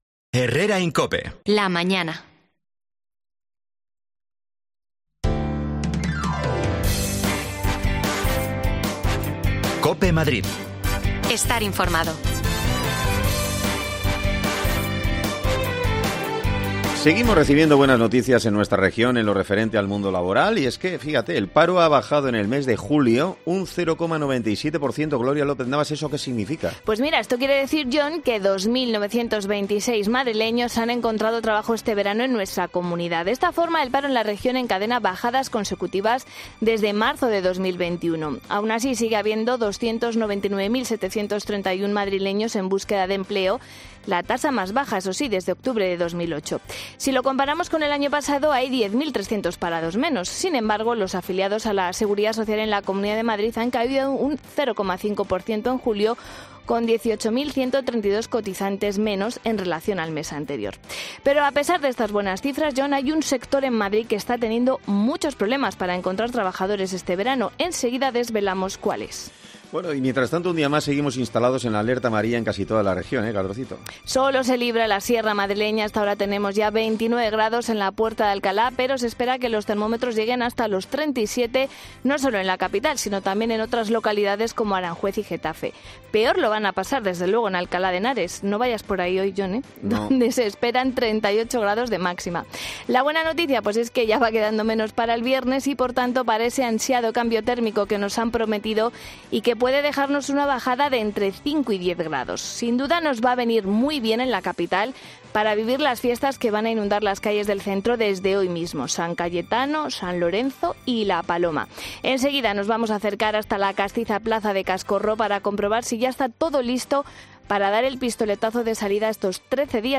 Arrancan las fiestas más castizas de Madrid. Todo está listo ya en la Plaza de Cascorro para honrar a San Cayetano. Nos acercamos a comprobarlo.
Te contamos las últimas noticias de la Comunidad de Madrid con los mejores reportajes que más te interesan y las mejores entrevistas, siempre pensando en el ciudadano madrileño.